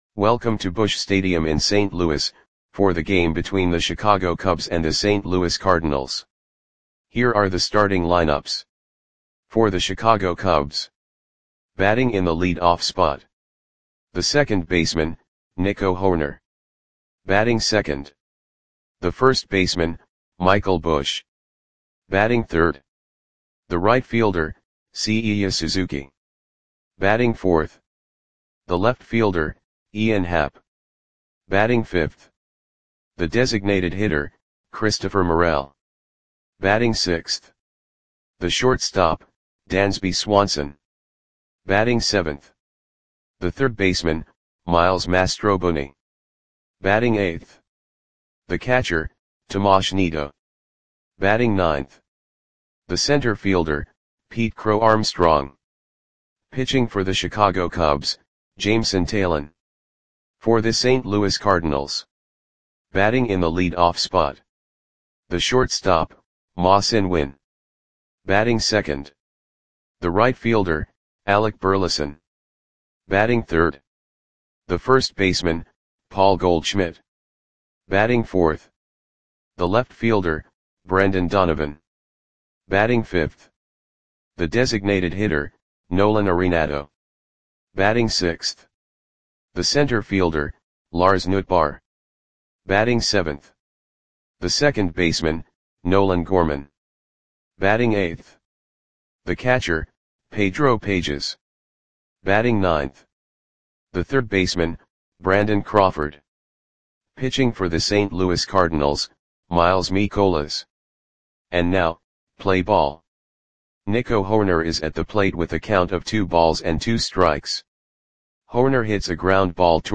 Audio Play-by-Play for St. Louis Cardinals on July 14, 2024
Click the button below to listen to the audio play-by-play.